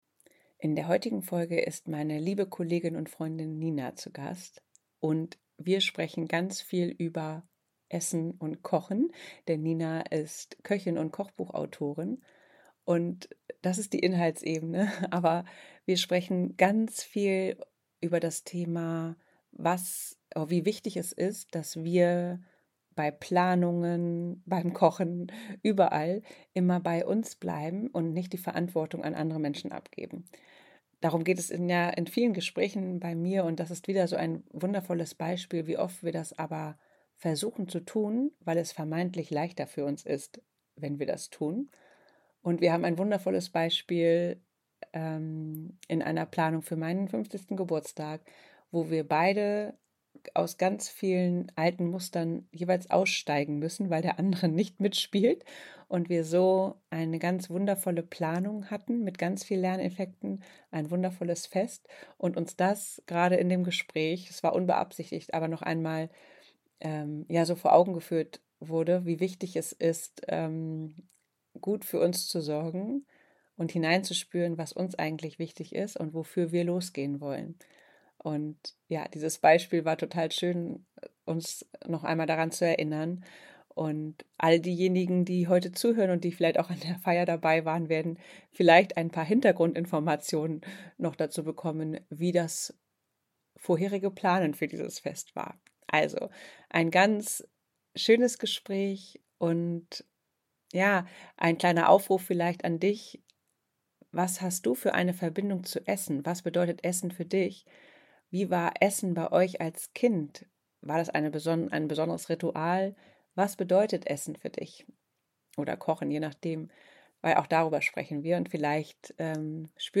Ein warmes, ehrliches Gespräch über Klarheit, Verbundenheit und das Funkeln, das entsteht, wenn wir einfach wir selbst sind.